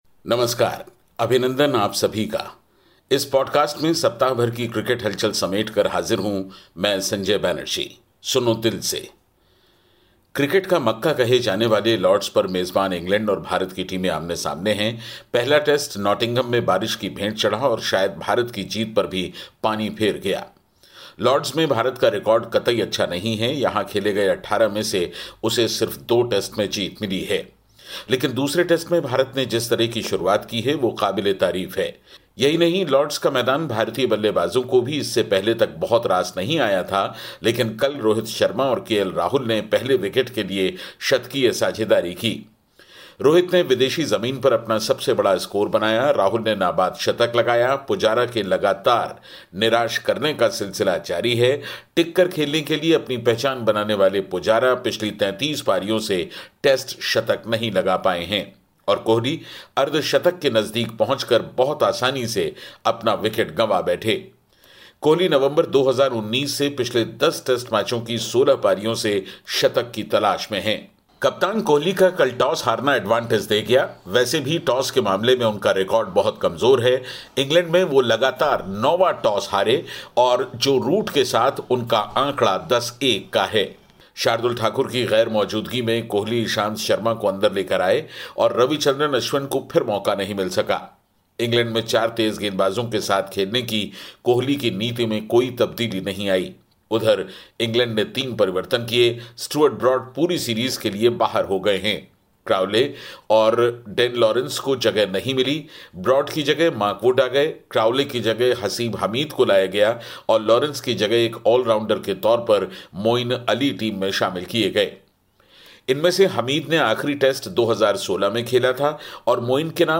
मशहूर कॉमेंटेटर संजय बैनर्जी सप्ताह भर की खेल की ये सब खबरें लेकर आपके सामने हैं.